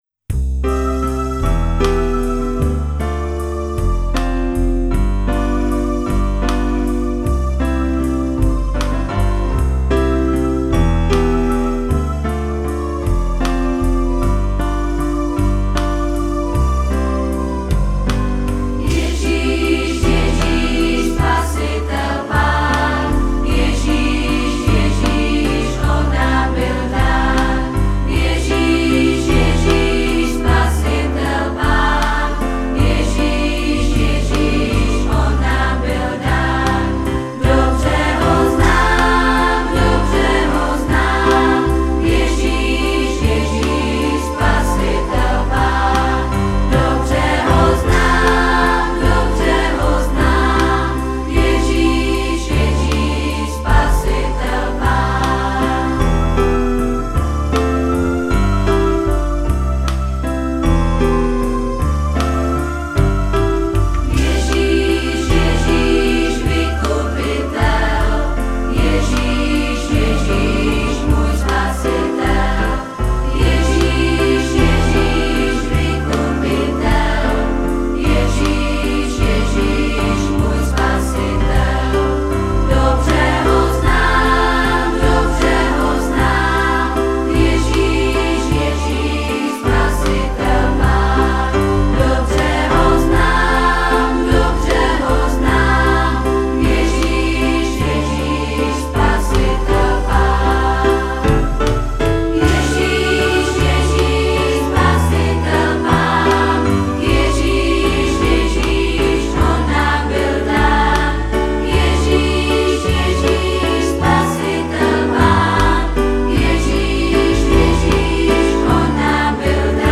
Písničky pro děti